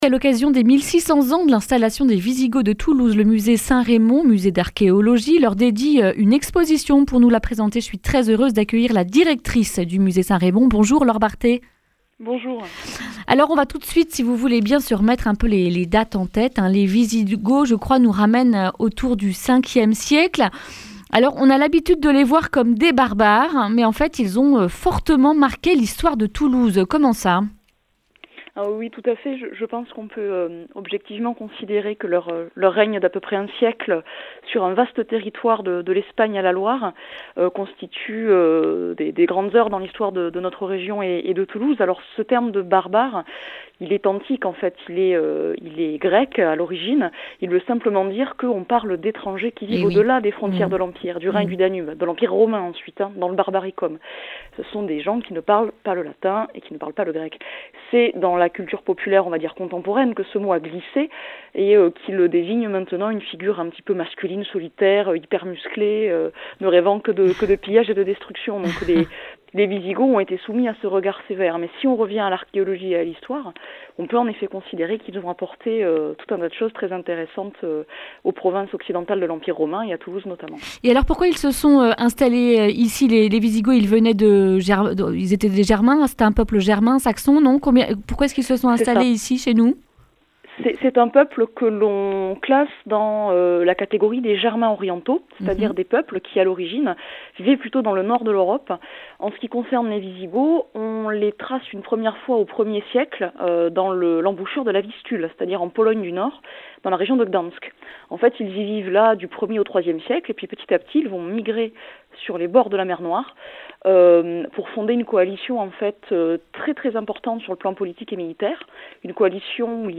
mardi 3 mars 2020 Le grand entretien Durée 10 min